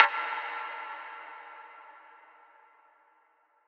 Bat [ Metro ].wav